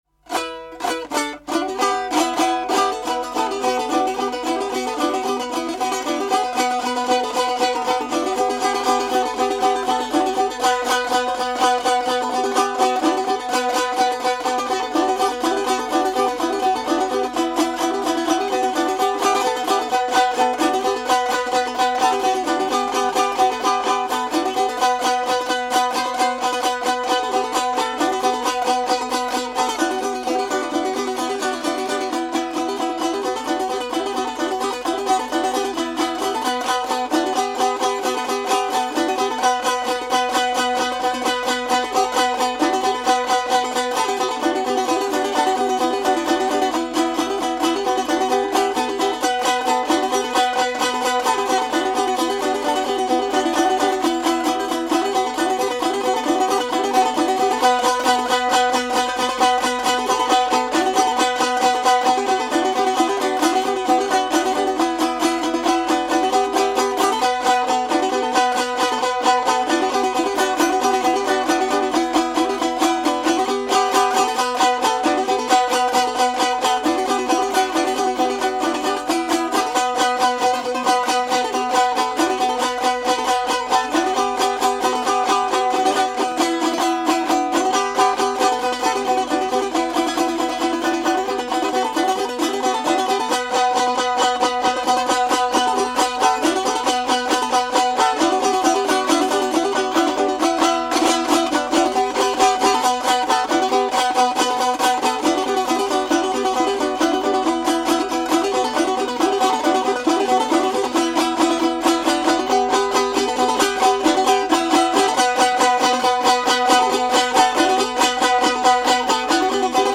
ОБЪЕКТЫ НЕМАТЕРИАЛЬНОГО КУЛЬТУРНОГО НАСЛЕДИЯ ПСКОВСКОЙ ОБЛАСТИ
Инструментальные особенности игры на балалайке в Порховском р-не (по материалам экспедиций ФЕЦ)